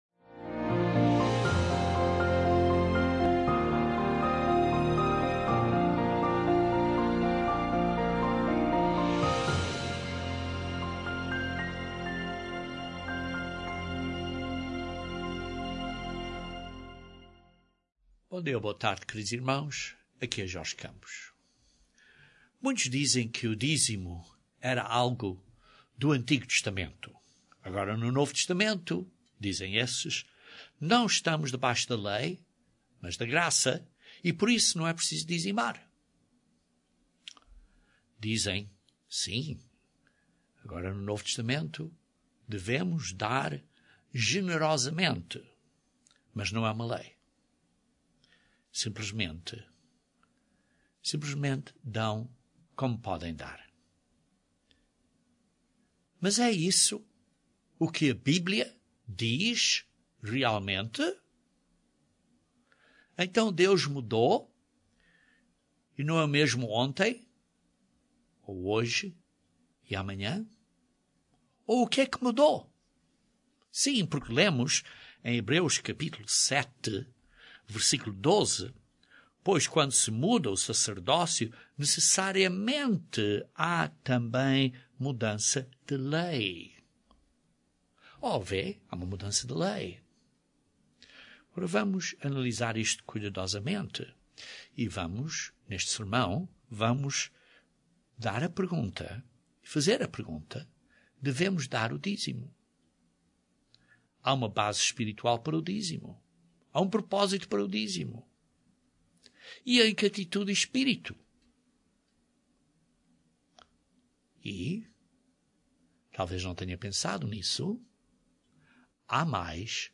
Este sermão aborda estas importantes questões.